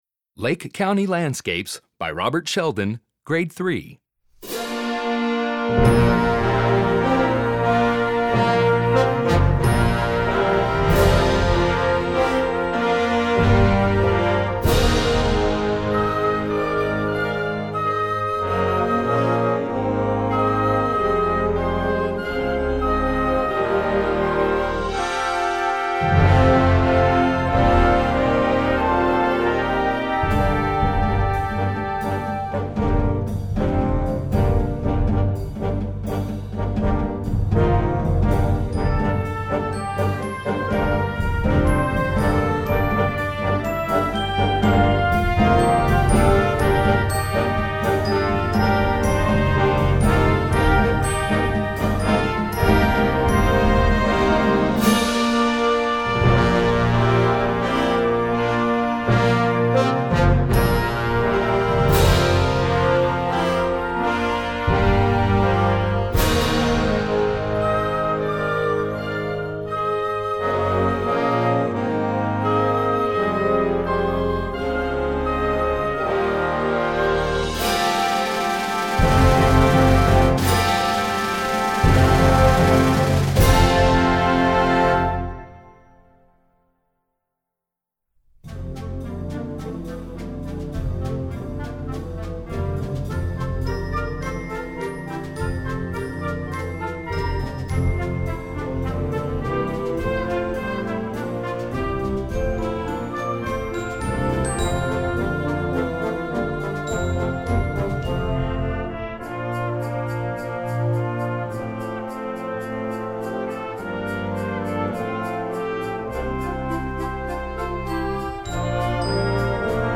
Gattung: Mini-Suite
Besetzung: Blasorchester